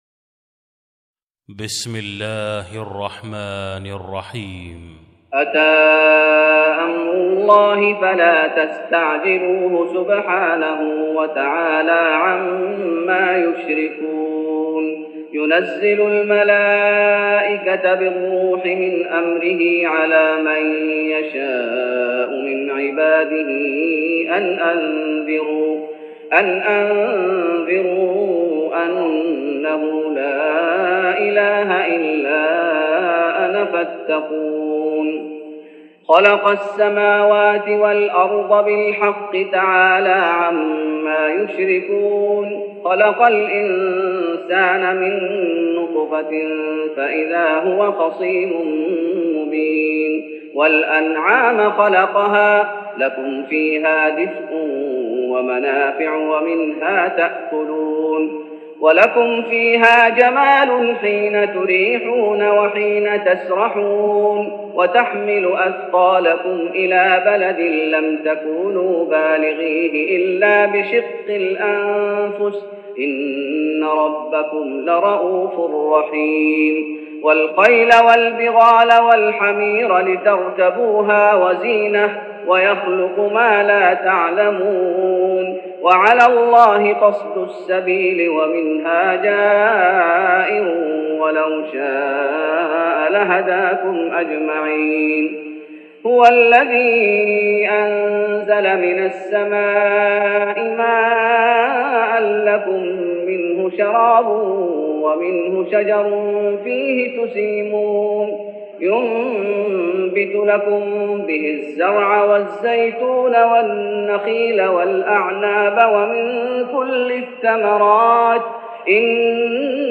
تهجد رمضان 1413هـ من سورة النحل (1-43) Tahajjud Ramadan 1413H from Surah An-Nahl > تراويح الشيخ محمد أيوب بالنبوي 1413 🕌 > التراويح - تلاوات الحرمين